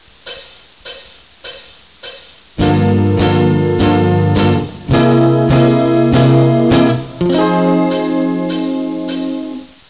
В конце концов, я решил использовать замену - я сыграл B-мажорную гамму в седьмой позиции (A#[Bb]) на аккорд Bb7#5#9.